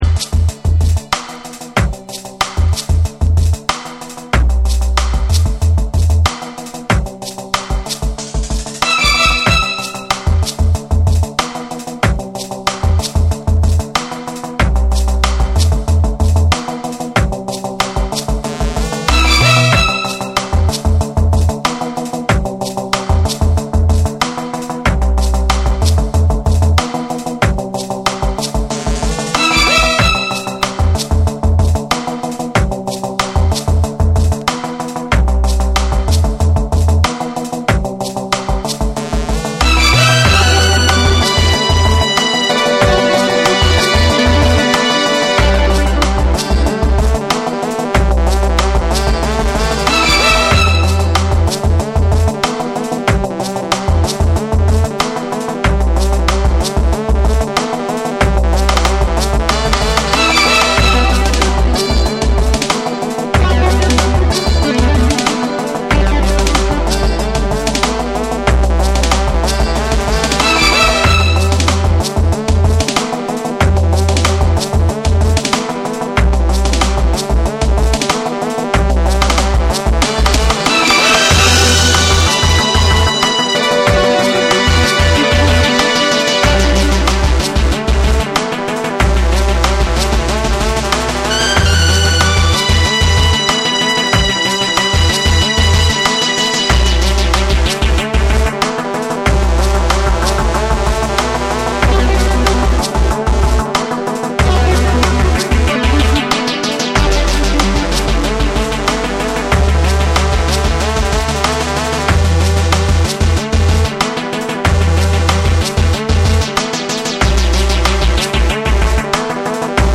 メロディックで甘さのあるフレーズが際立つ
BREAKBEATS / DUBSTEP